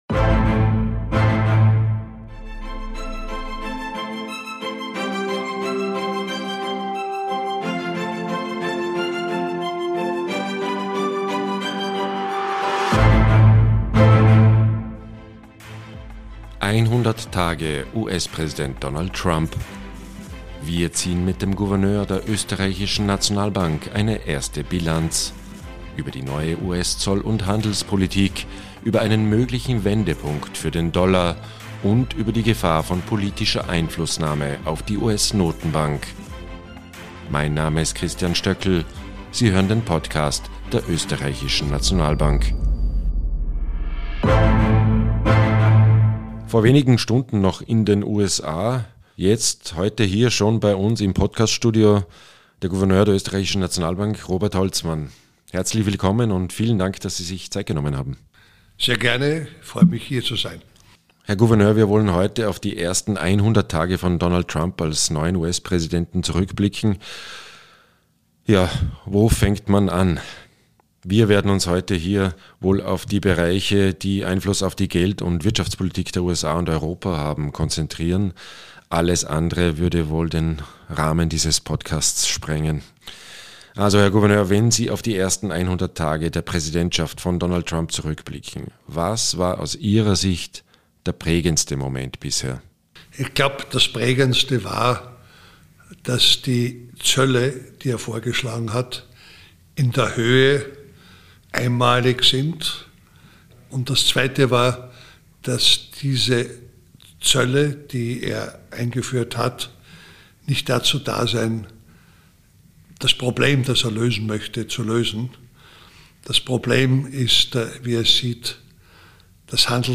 Im Fokus stehen vorallem Trumps Entscheidungen, die Einfluss auf die Geld- und Wirtschaftspolitik der USA und Europa haben, wie etwa die neue US-Zollpolitik, der Handelskrieg zwIschen den USA und China und die Gefahr von politischer Einflussnahme auf die amerikanische Notenbank FED. Zu Gast: OeNB-Gouverneur Robert Holzmann Mehr